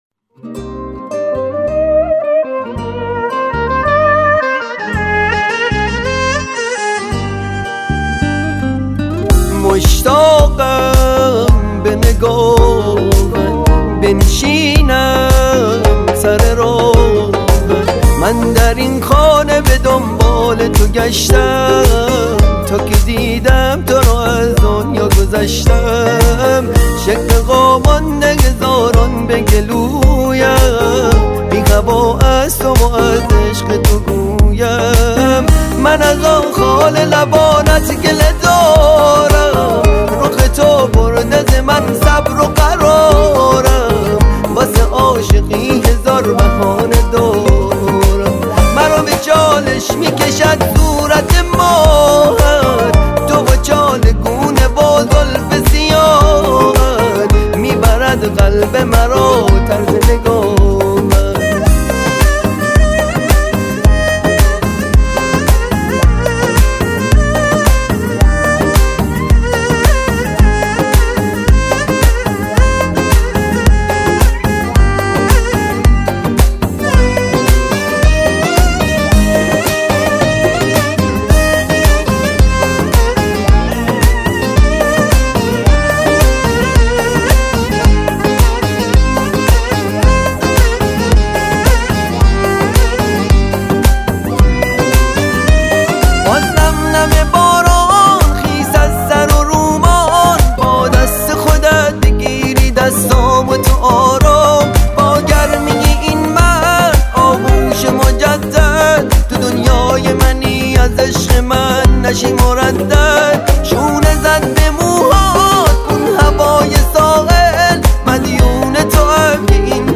یک ترانه عاشقانه
در سبک پاپ